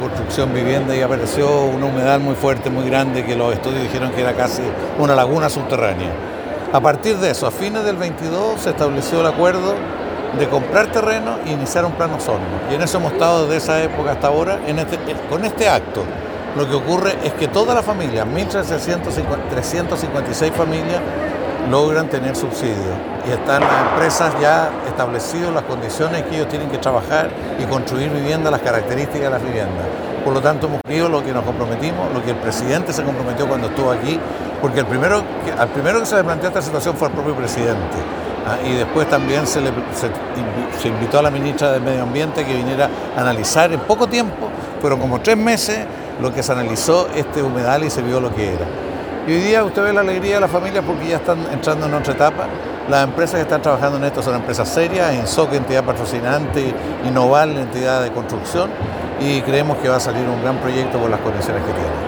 En una emotiva ceremonia realizada este martes en el Gimnasio María Gallardo de Osorno, 940 familias pertenecientes a los proyectos habitacionales Lomas de Ovejería II y Alberto Fuchslocher recibieron sus subsidios habitacionales.
21-noviembre-24-carlos-montes-subsidios.mp3